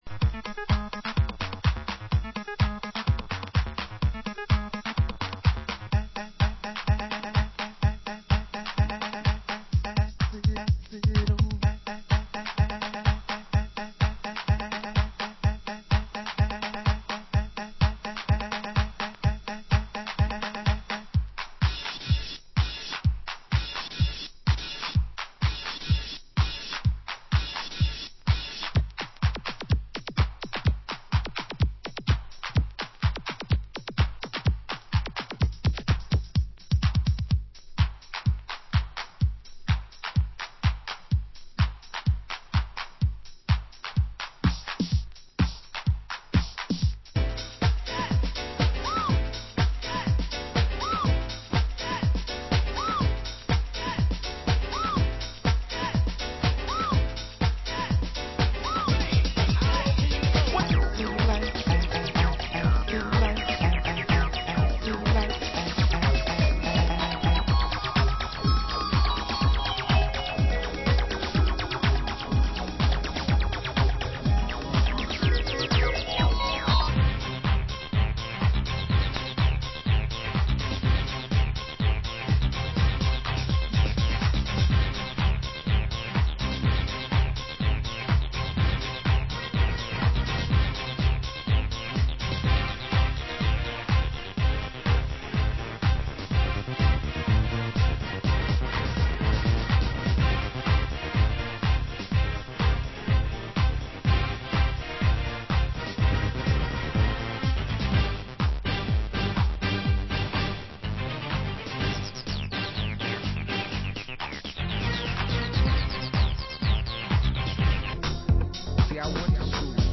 Genre: US House